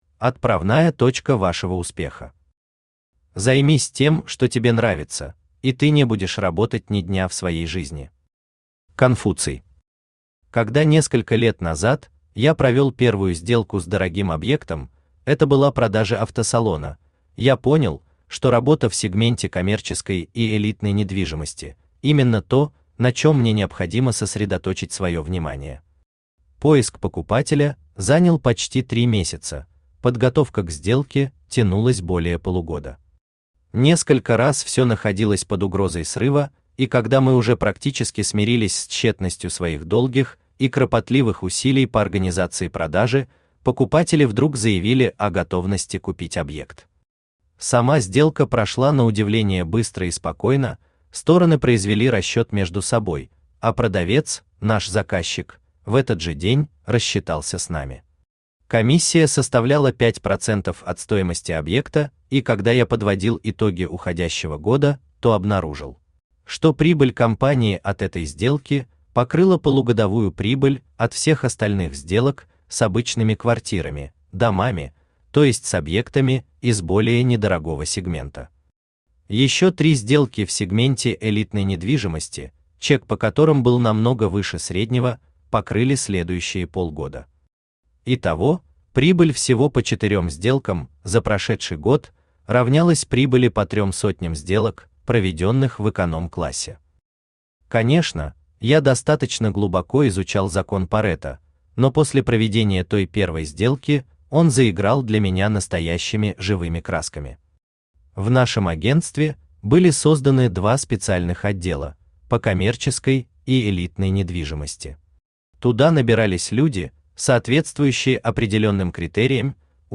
Аудиокнига Правила выстраивания отношений с собственниками дорогой недвижимости | Библиотека аудиокниг
Aудиокнига Правила выстраивания отношений с собственниками дорогой недвижимости Автор Вячеслав Александрович Егоров Читает аудиокнигу Авточтец ЛитРес.